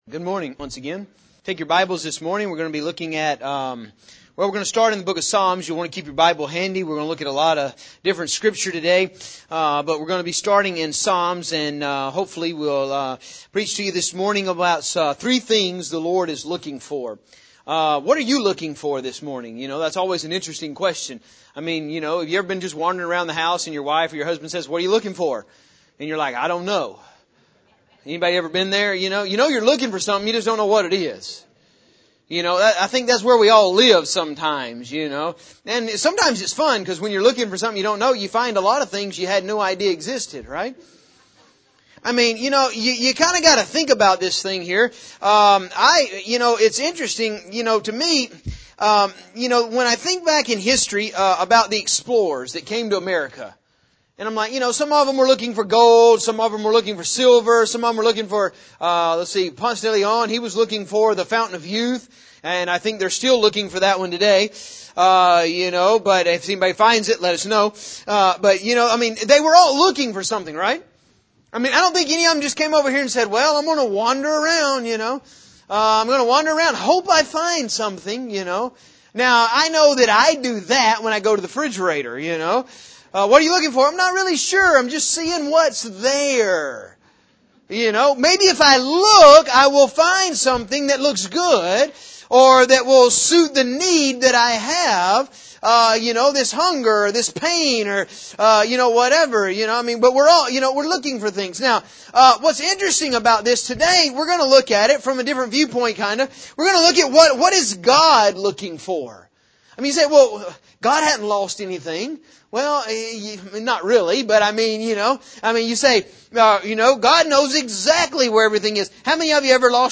The verse says that God was looking for something, so in this sermon we will use the word of God to show us several examples of what God is looking for.